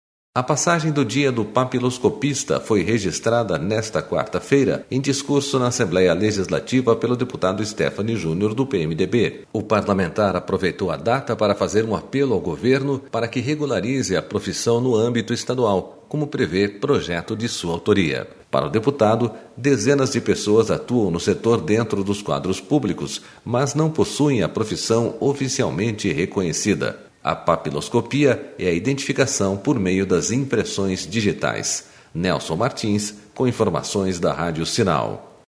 A passagem do Dia do Papiloscopista foi registrada nesta quarta-feira em discurso na Assembleia Legislativa, pelo deputado Stephanes Junior, do PMDB.//O parlamentar aproveitou a data para fazer um apelo ao Governo para que regularize a profissão no âmbito estadual, como prevê projeto de sua autoria....